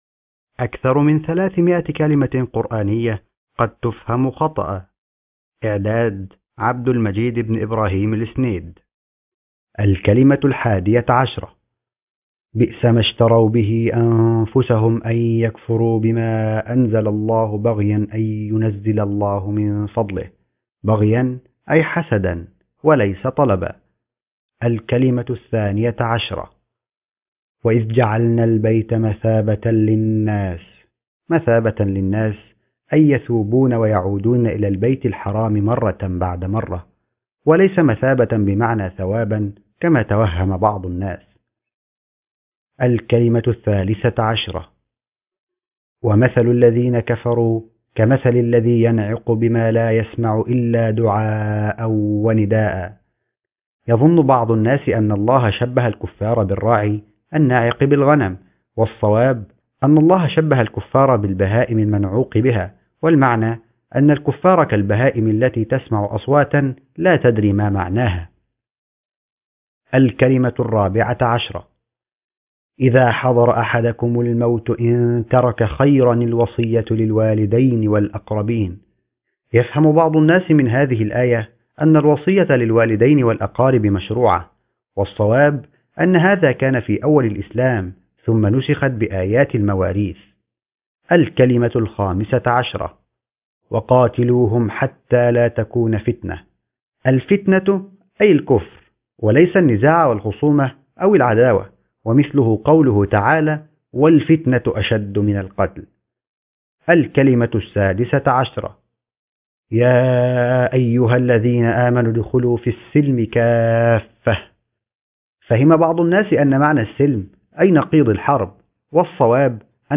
أكثر من 300 كلمة قرآنية قد تفهم خطأ ( كتاب صوتي مقروء )